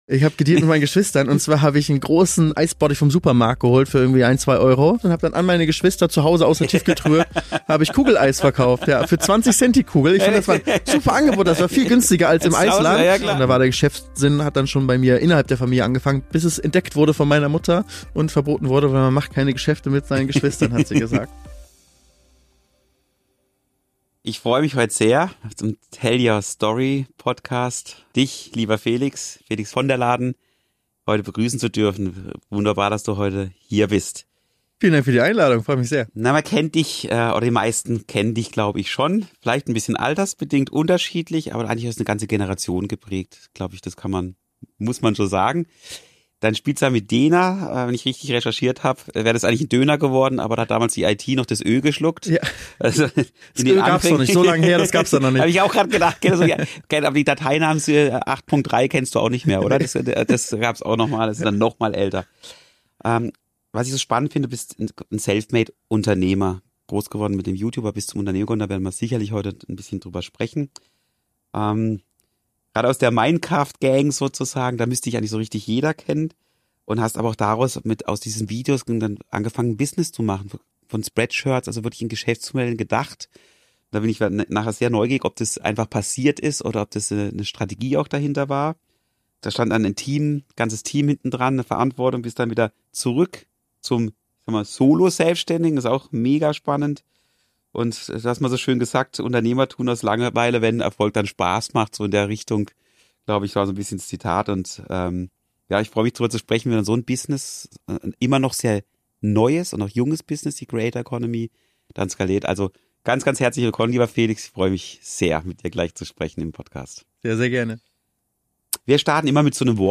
Ein inspirierendes Gespräch über Spaß als Treiber, Unternehmertum, Verantwortung, Kreativität und die Kunst, sich immer wieder neu zu erfinden.